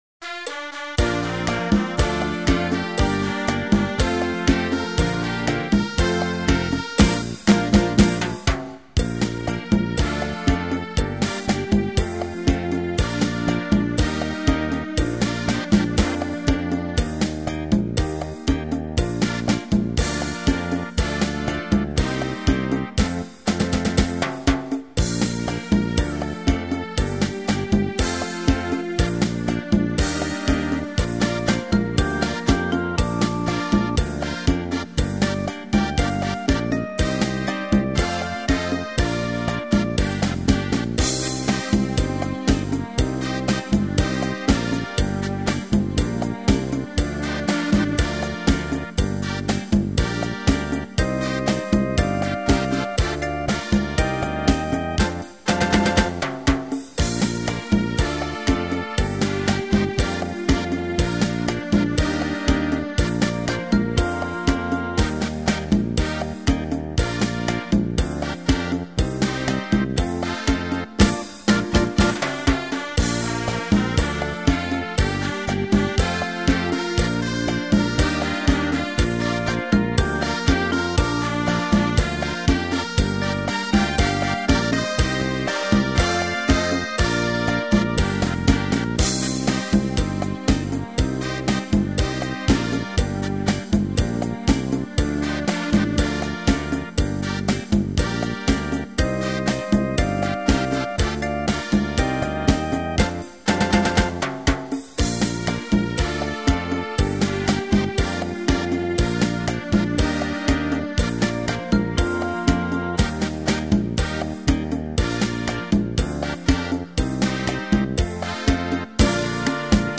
เพลงมาร์ชวิทยาลัย
บรรเลง